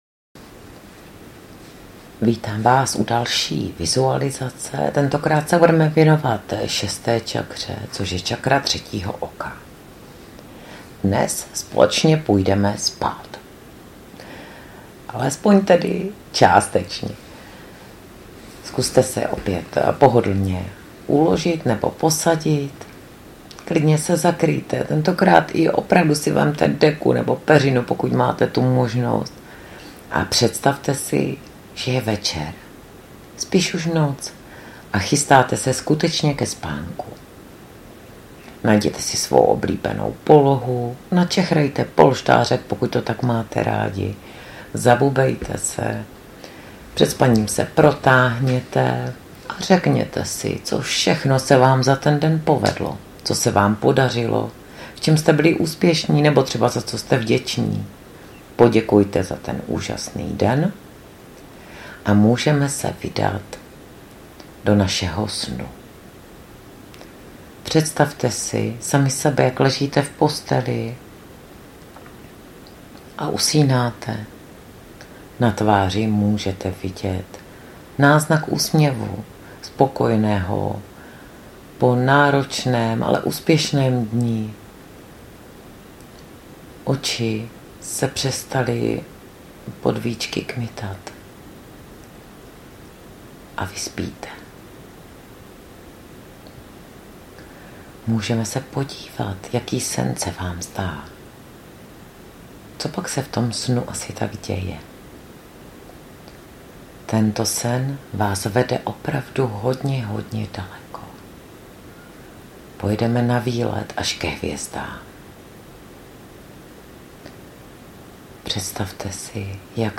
Vizualizace-6.-cakra.mp3